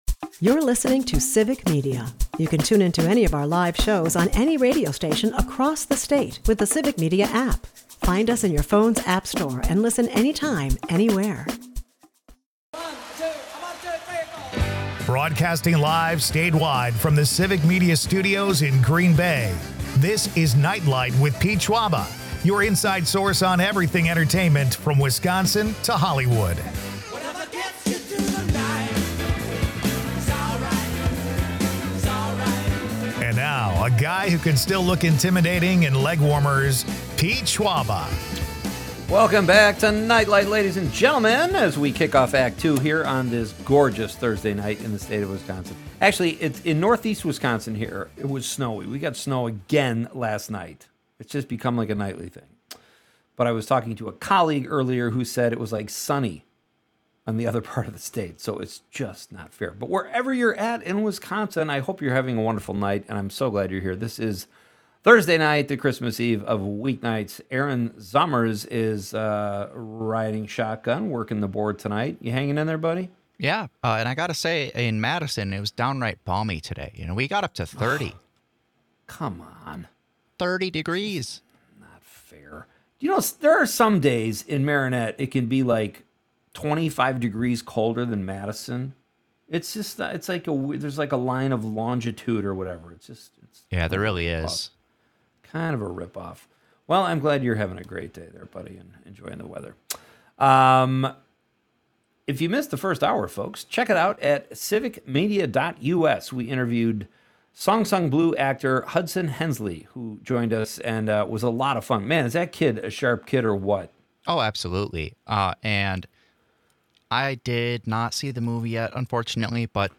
Civic Media radio network